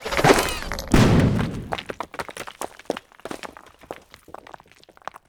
grenade.wav